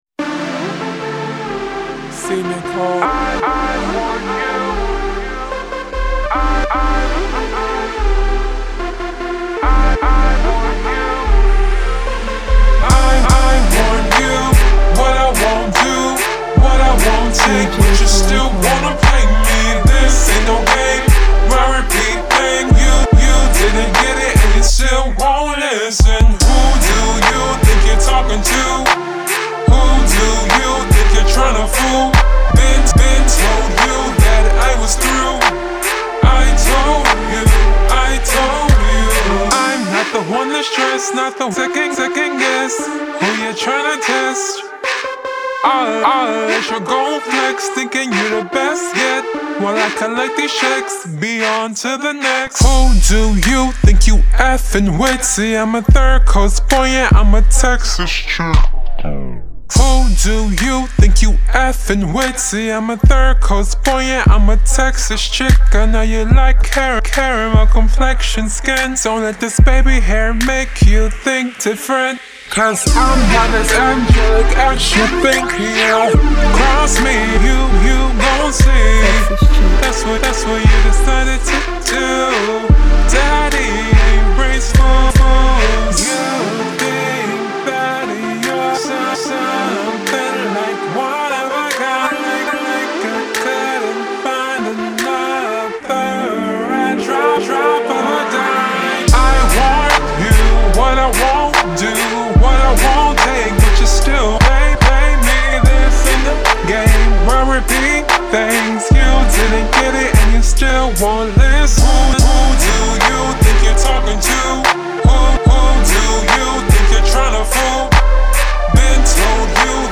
Genre: Urban Pop